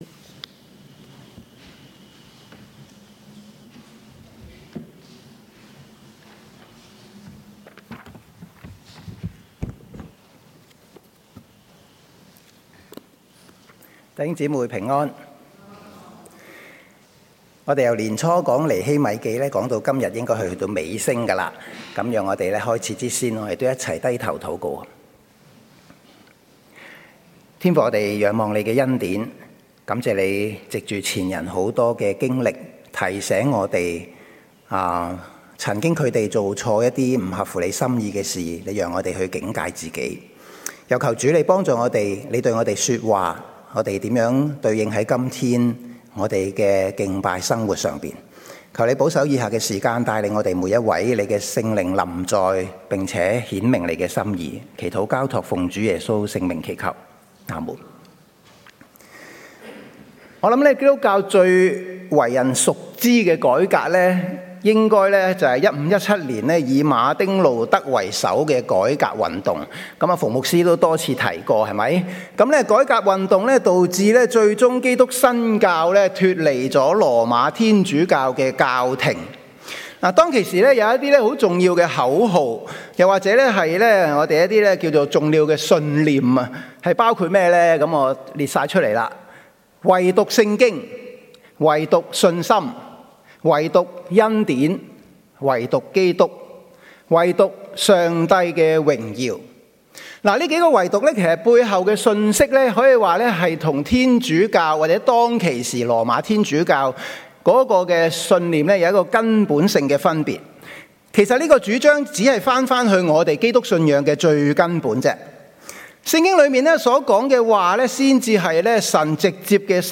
崇拜錄音 (Cantonese Only) - The Chinese Christian Church